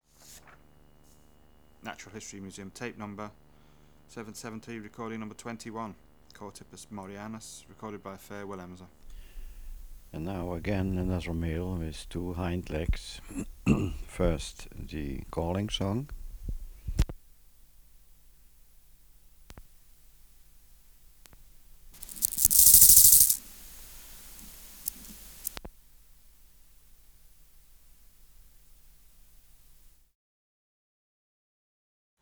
Recording Location: Room in private house.
In cage with a silent 1-legged male and a silent 2-legged male. Calling song from a male with 2 hind legs
Microphone & Power Supply: AKG D202E (LF circuit off)